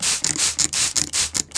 SPRAY CAN 2.WAV